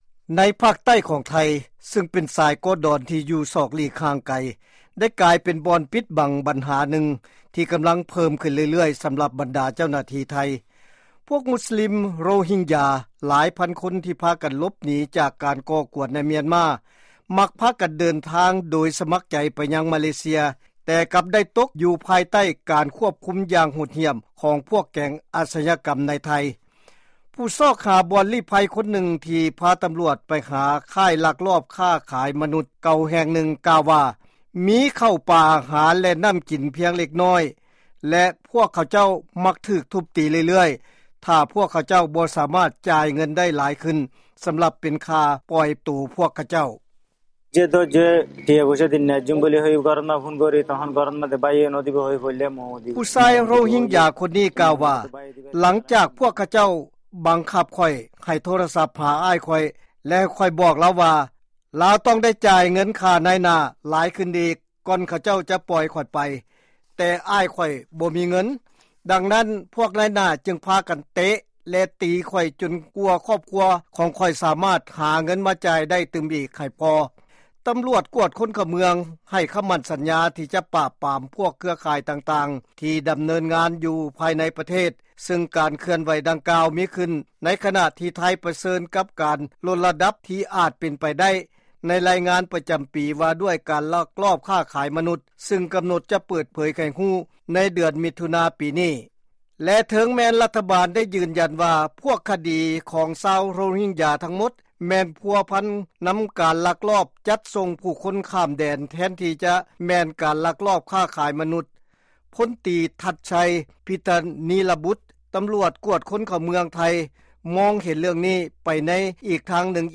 ຟັງລາຍງານກ່ຽວກັບການລັກລອບຄ້າຂາຍມະນຸດ ທີ່ໄທ